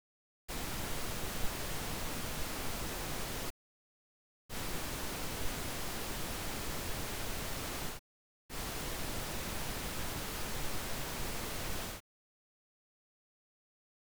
sinistra-destra x 2 / in fase - in controfase (6" + 6")
rumore_rosa_-16dB-sin-des-sin-des.wav